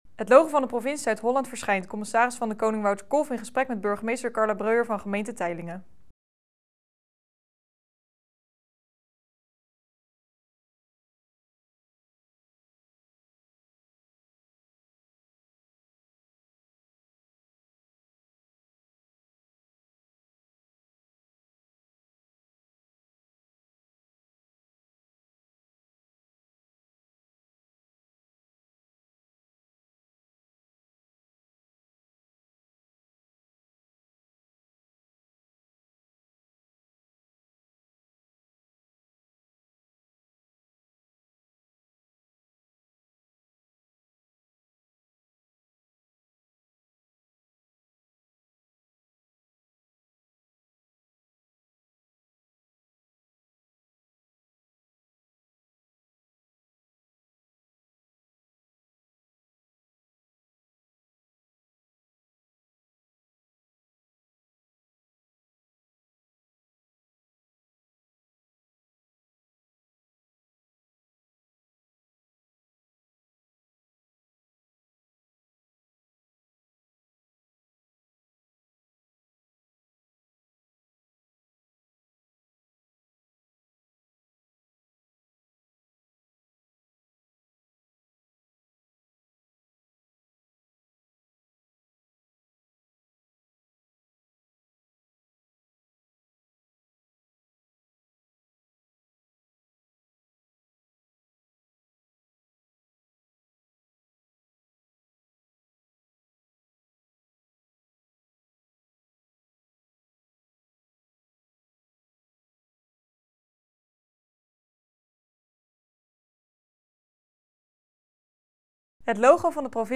CdK in gesprek met burgemeester Teylingen